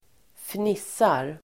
Ladda ner uttalet
fnissa verb, giggle, titterGrammatikkommentar: A &Uttal: [²fn'is:ar] Böjningar: fnissade, fnissat, fnissa, fnissarSynonymer: flina, fnittraDefinition: småskratta, fnittra
fnissar.mp3